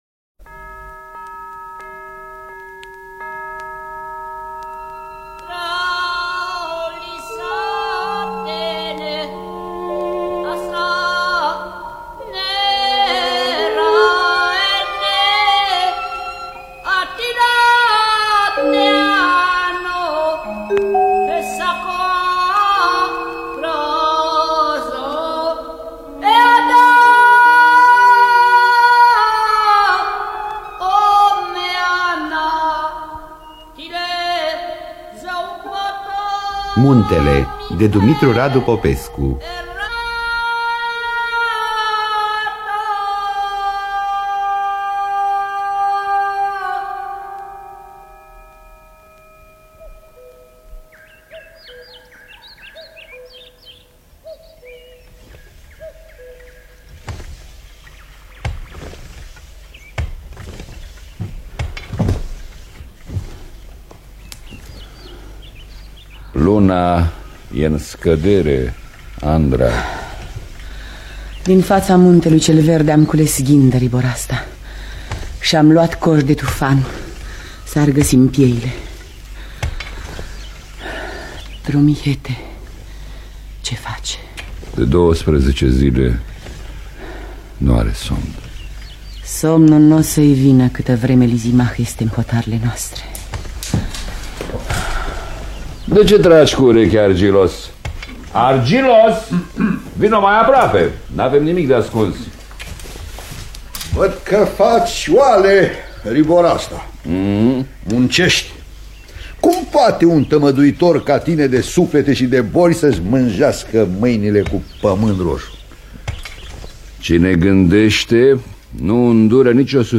Adaptarea radiofonică de Nae Cosmescu.